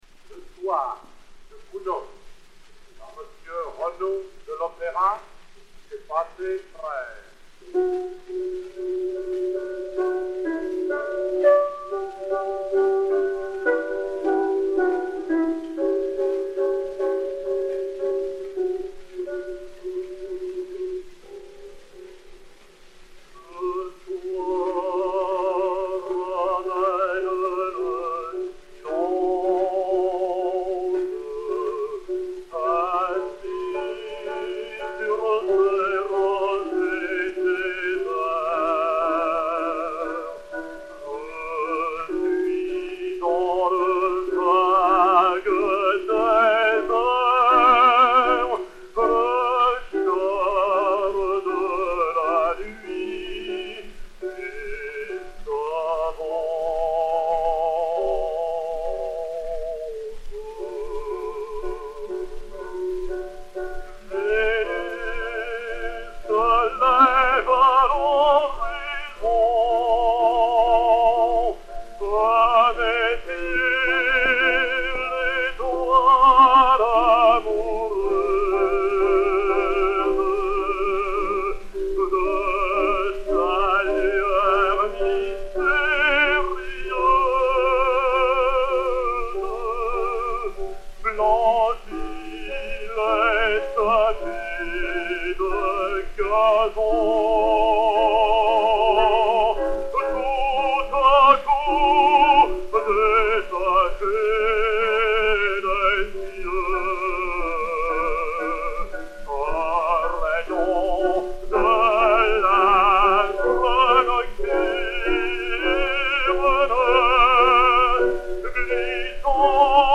Mélodie, poésie d’Alphonse Marie Louis DE PRAT DE LAMARTINE dit Alphonse DE LAMARTINE (Mâcon, Saône-et-Loire, 21 octobre 1790 – Paris 16e, 28 février 1869*), musique de Charles GOUNOD.
Maurice Renaud et Piano